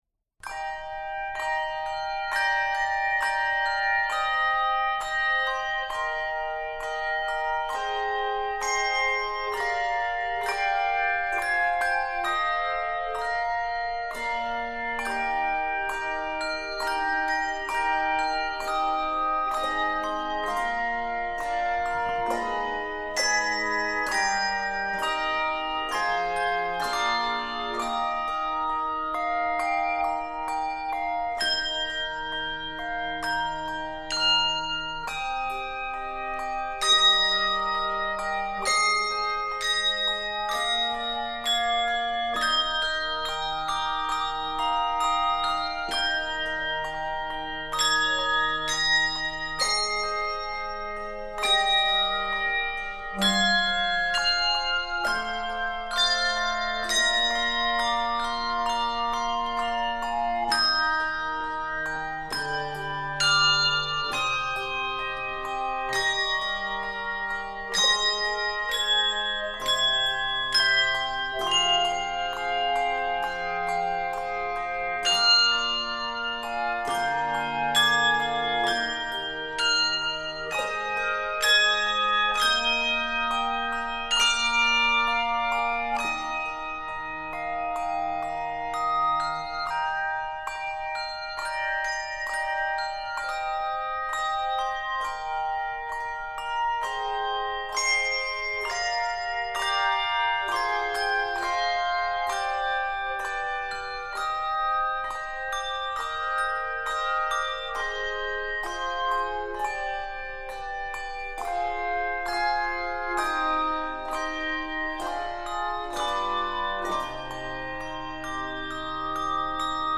This quiet and meditative arrangement
Keys of Eb Major and c minor.